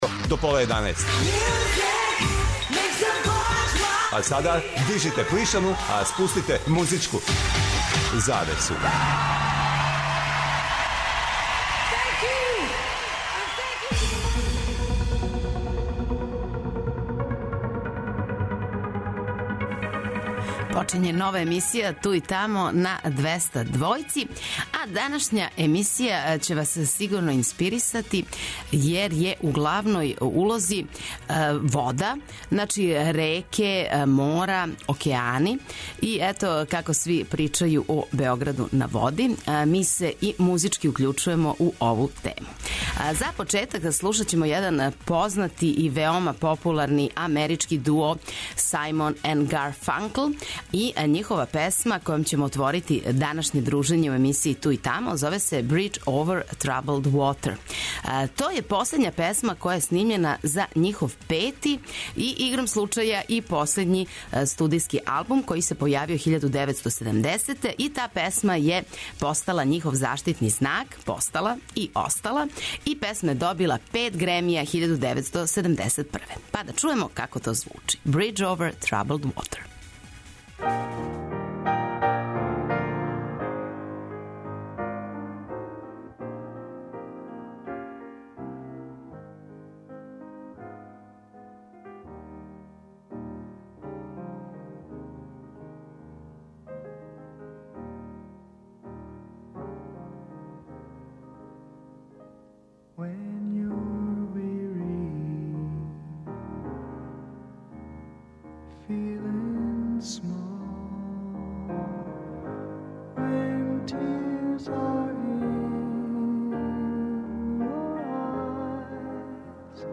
Док се будите и пијете јутарњу кафу обавезно појачајте 'Двестадвојку' јер вас очекују велики хитови страни и домаћи, стари и нови, супер сарадње, песме из филмова, дуети и још много тога.